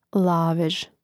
lávež lavež